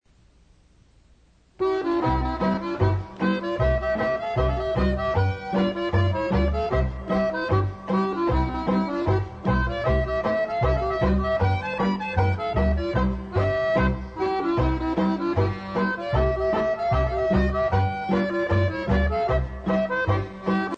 Cleveland Style (Slovenian)